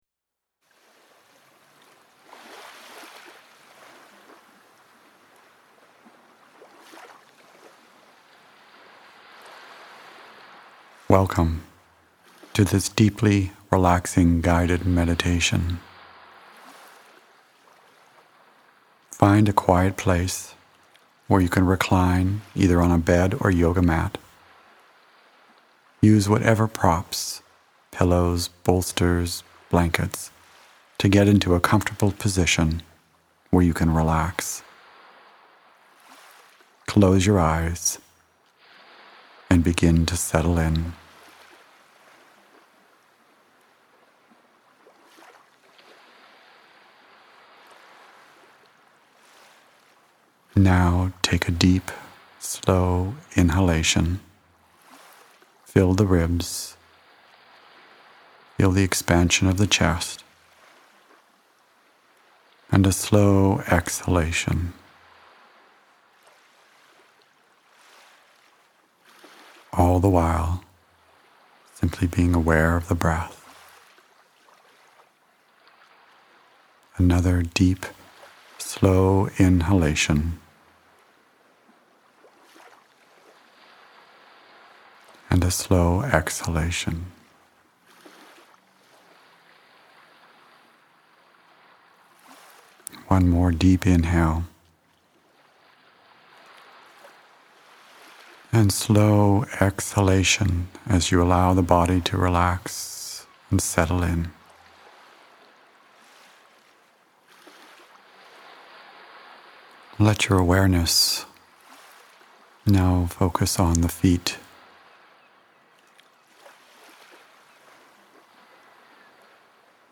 Guided Relaxation & Healing
Guided-Meditation-Healing-white-light.mp3